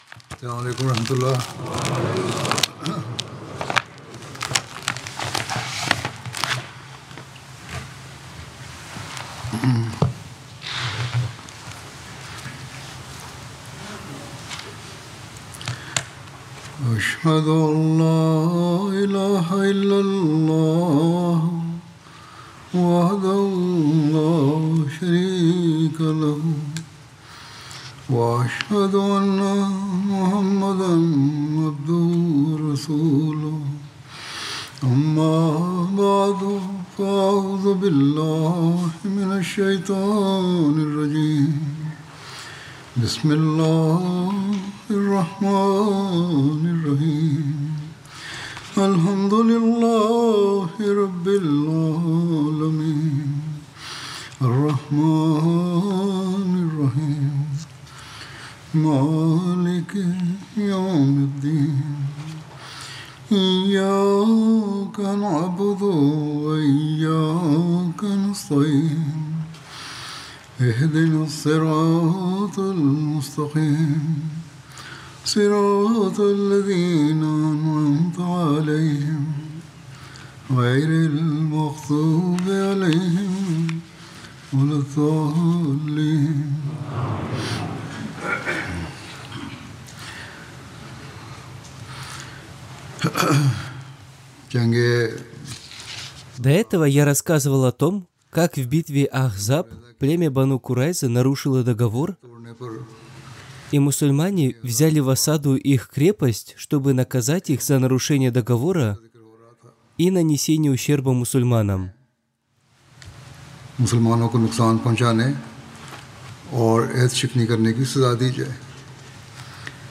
Пятничная проповедь Хузура от 8 ноября 2024 – Пятничные проповеди 2024 года Хазрата Мирзы Масрура Ахмада (да поможет ему Аллах) – Podcast – Podtail